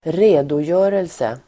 Uttal: [²r'e:dojö:relse]